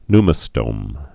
(nmə-stōm, ny-)